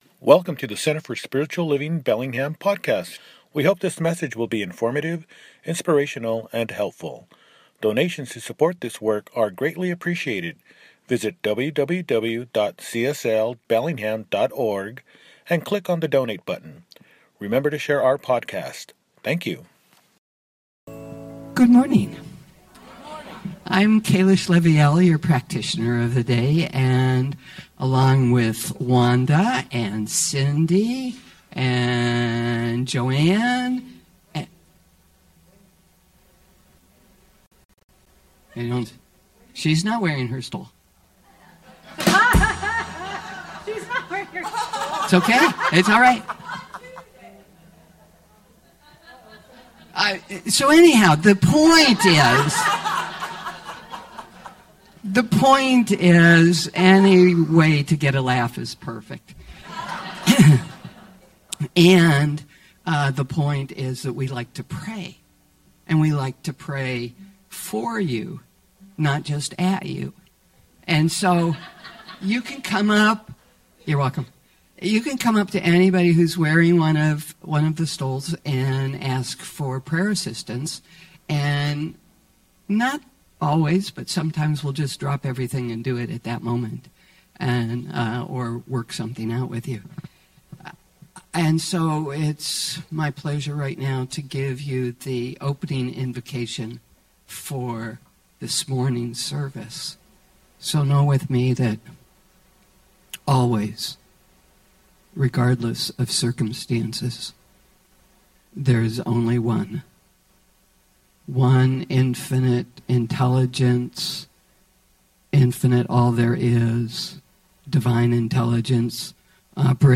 Surrendering into Newness – Celebration Service